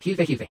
ggl_es-US-Wavenet-B_*200.wav